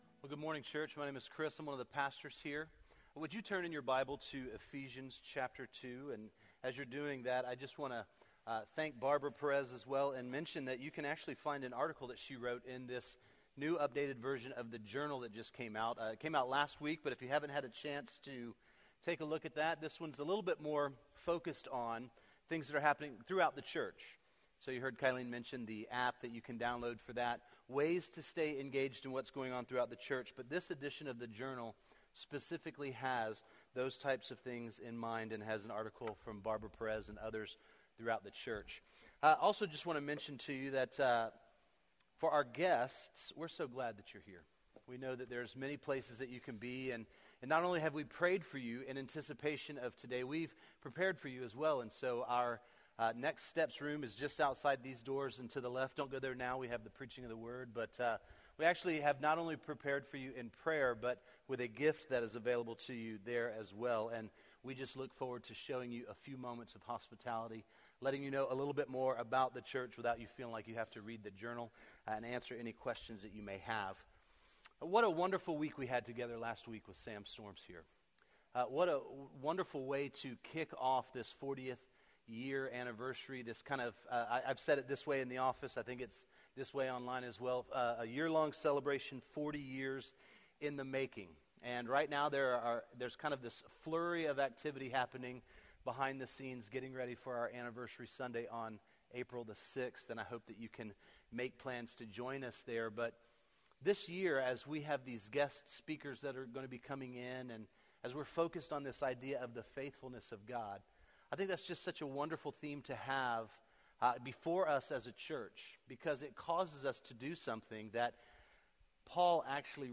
Metro Life Church Sermons
This page contains the sermons and teachings of Metro Life Church Casselberry Florida